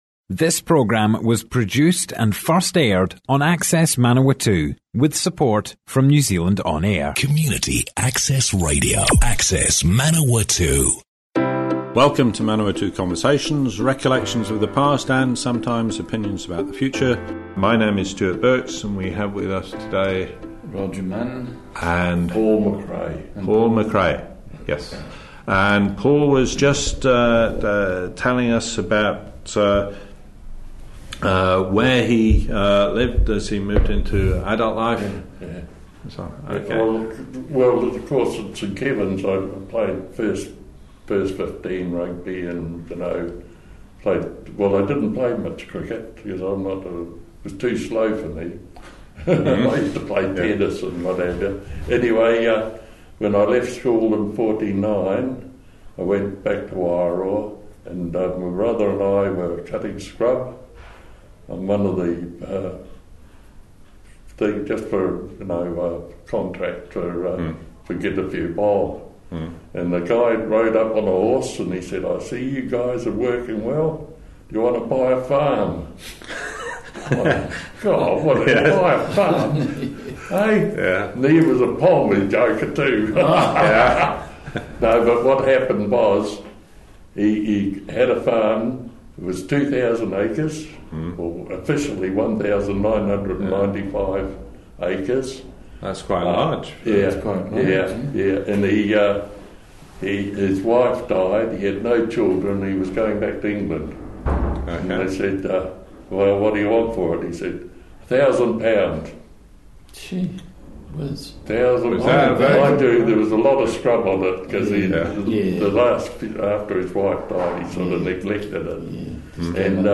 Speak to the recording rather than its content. Manawatu Conversations More Info → Description Broadcast on Access Radio